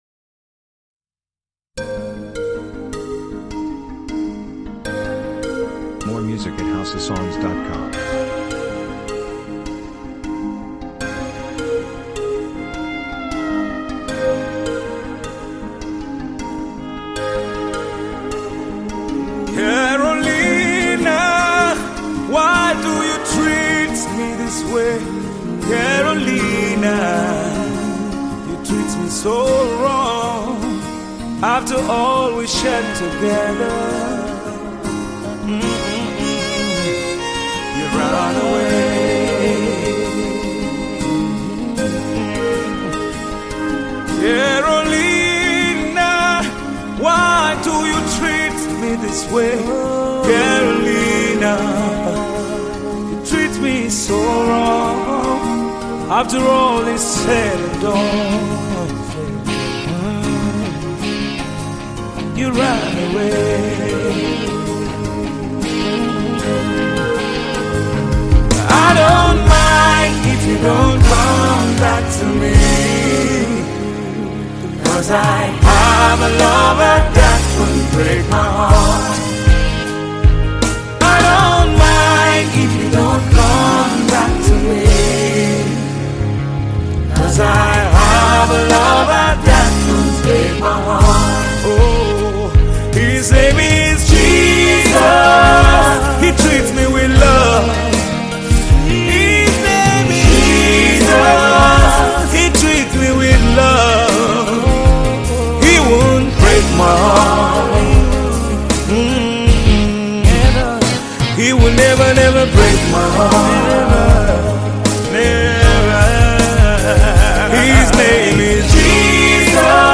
encouraging, uplifts the spirit and soul
Tiv Song